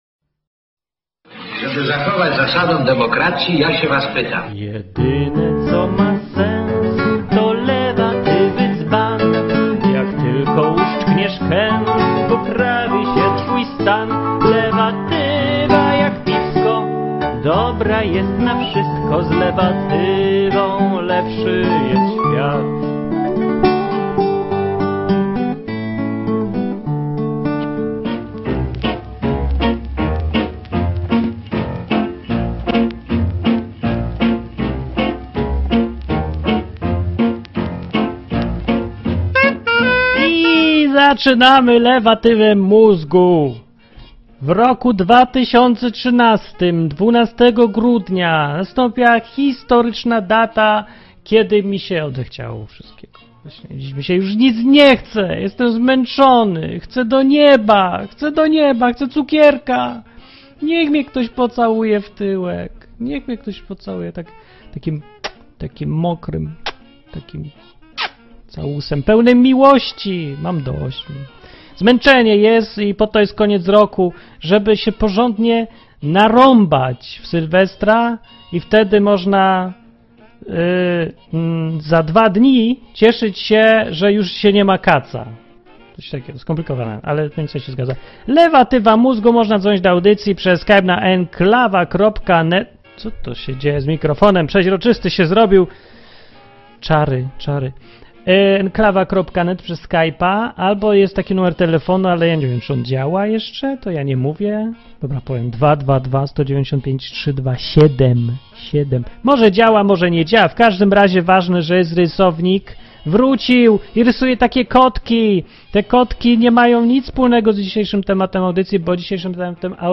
Dzisiejsza lewatywa bardzo emocjonalna.
Program satyryczny, rozrywkowy i edukacyjny.